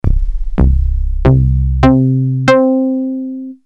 edit What's a lately Bass? it a famous electro bass sound generated by FM synthesis included as preset on various Yamaha modules or synthesizers.
solid bass all C (dx21 chorus OFF)
solid-bass-dx21.mp3